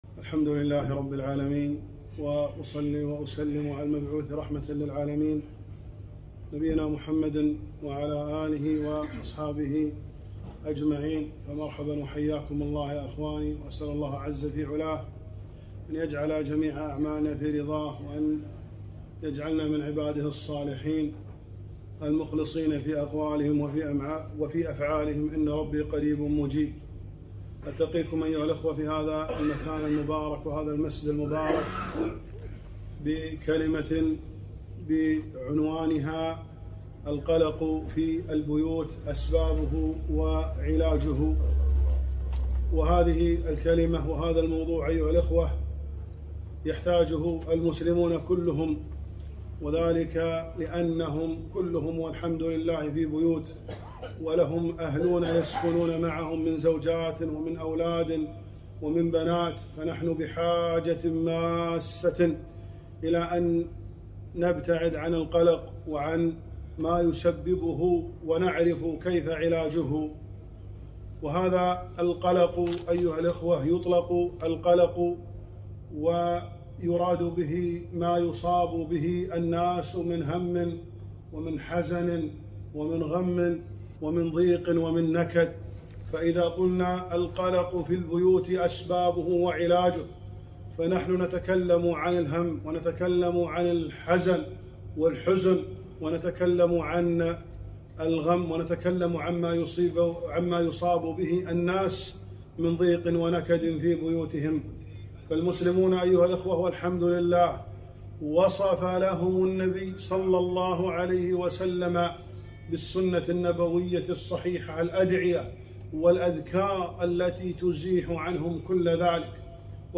محاضرة - القلق في البيوت أسبابه وعلاجه